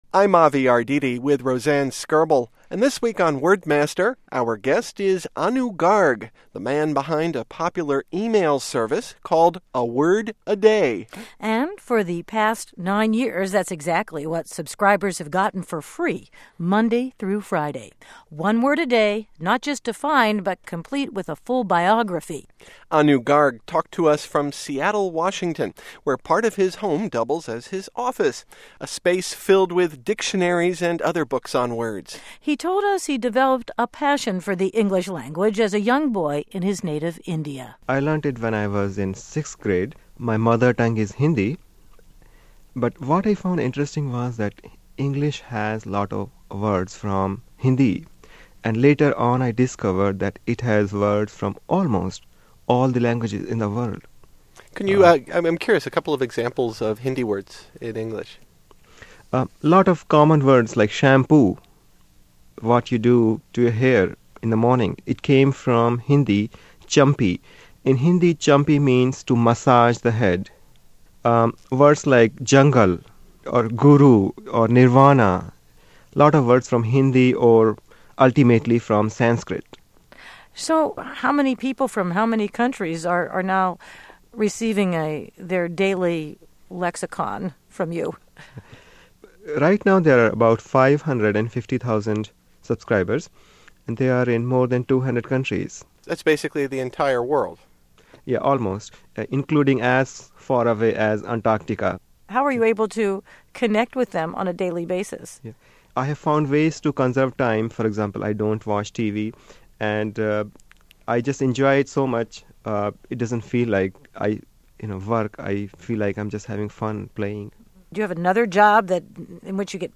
Broadcast: March 6, 2003